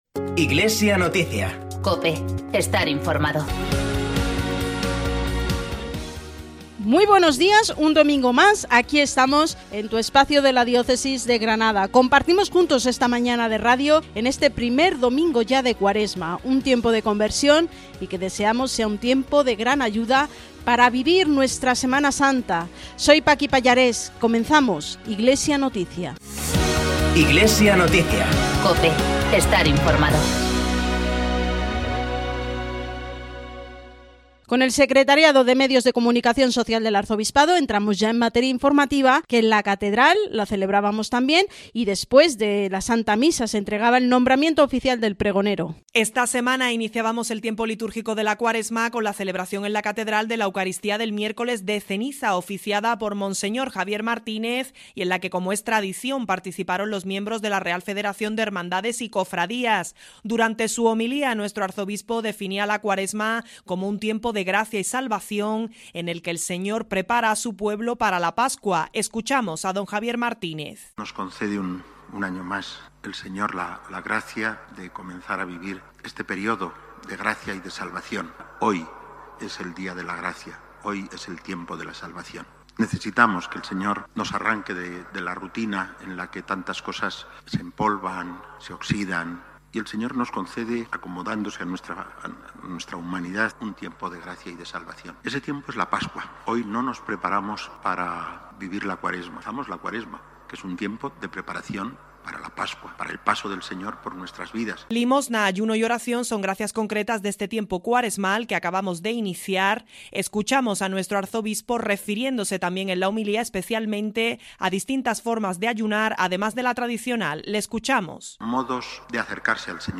Podcast del informativo emitido en COPE Granada.